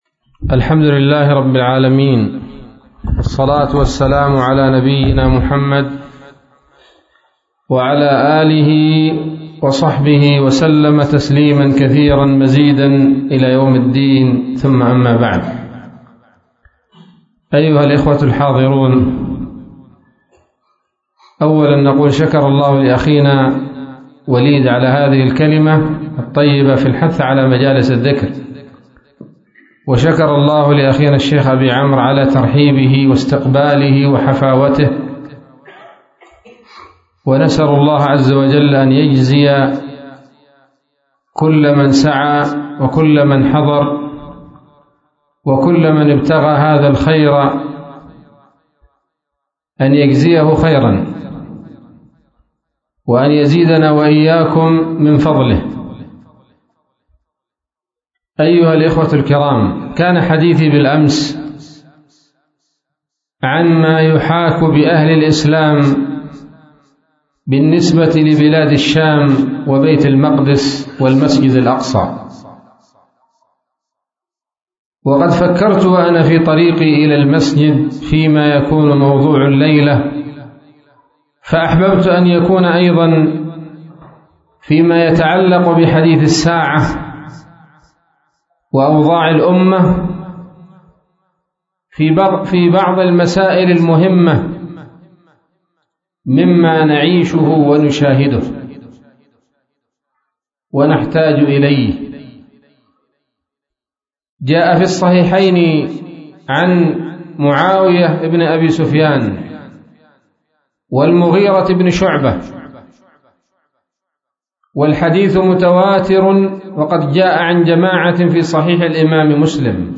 محاضرة قيمة